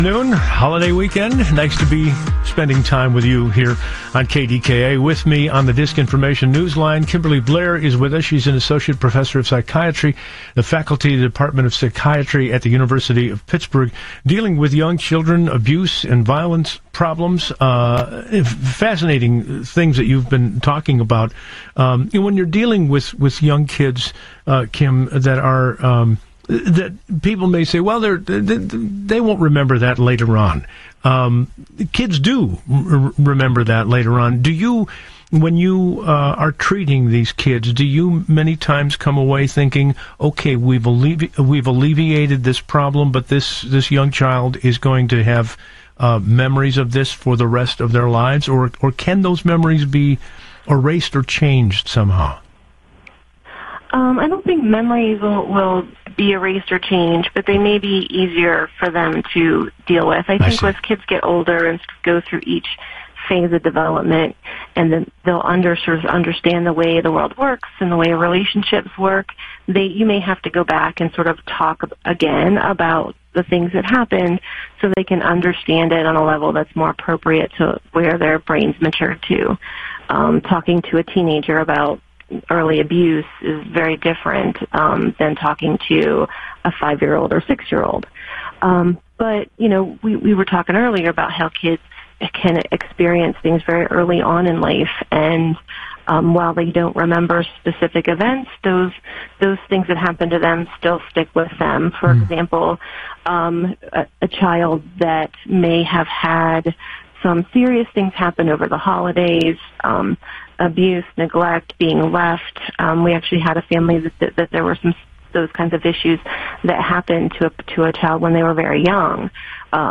KDKA Radio interview